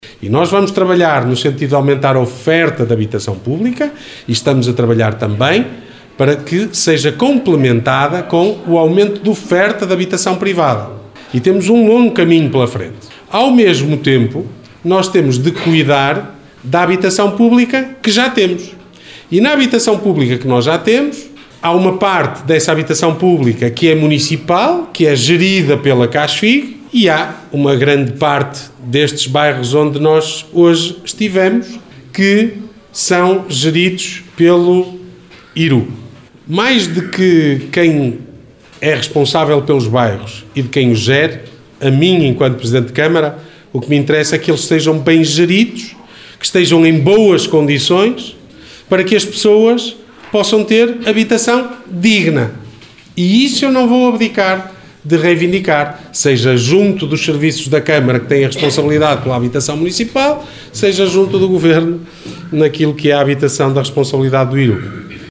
Declarações de Ricardo Araújo, presidente da Câmara Municipal de Guimarães. Falava ontem no final de uma visita aos Bairros da Emboladoura, Senhora da Conceição e Atouguia, acompanhado pela Secretária de Estado da Habitação, Patrícia Costa.